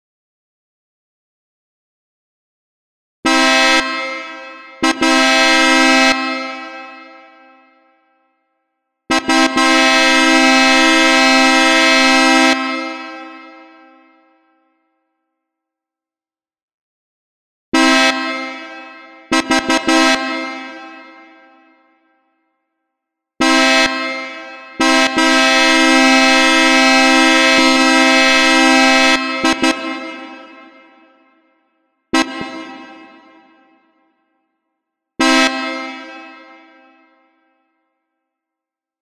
Tiếng Còi loa xe tải cỡ lớn bấm liên tục, vang dội
Thể loại: Tiếng chuông, còi
Description: Tiếng Còi loa xe tải cỡ lớn bấm liên tục, vang dội là tiếng còi lớn bấm liên tục của xe container thể hiện sự thúc giục từ tài xế, âm thanh còi lớn vang vọng thể hiện sự sốt ruột của người lái xe, âm thanh ồn ào từ còi xe lớn, tiếng xe tải bấm còi liên tục khi lưu thông trên đường tắc, tiếng còi xe báo hiệu cần được nhường đường.
Tieng-coi-loa-xe-tai-co-lon-bam-lien-tuc-vang-doi-www_tiengdong_com.mp3